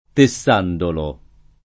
tesserandolo [ te SS er # ndolo ]